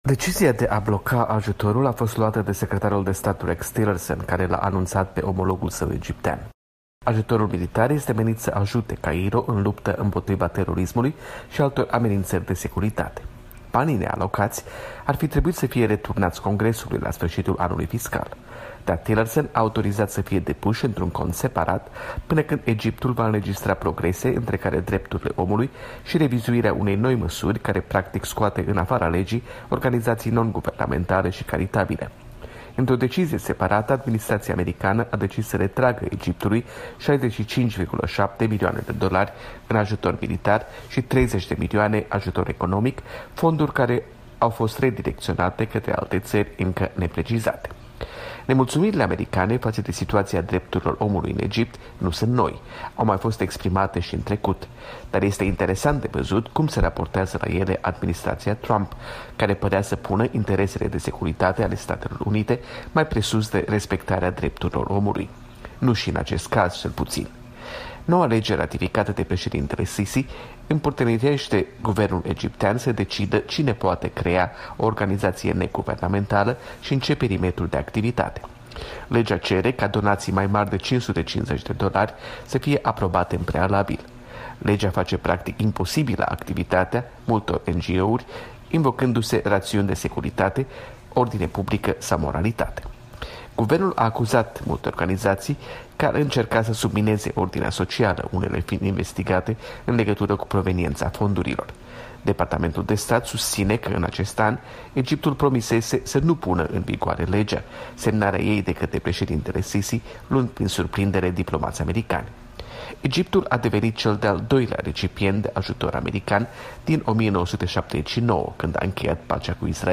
Corespondența zilei de la Wahington.